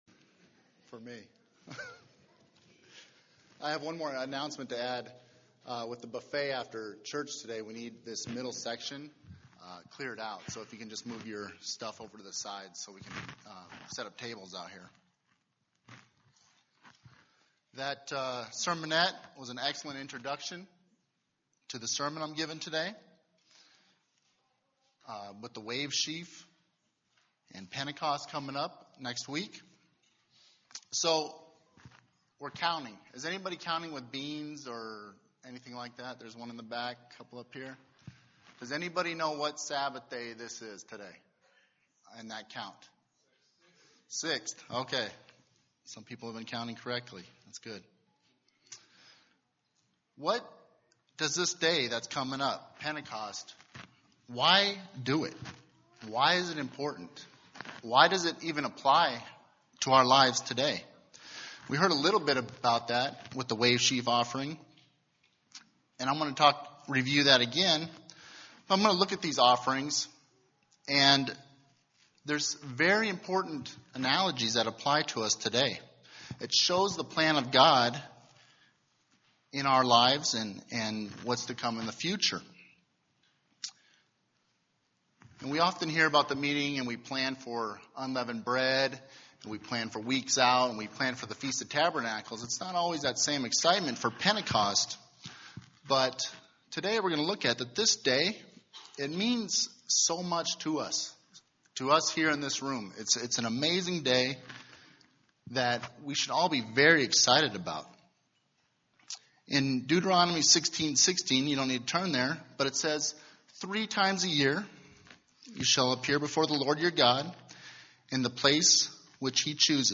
UCG Sermon Studying the bible?
Given in Phoenix East, AZ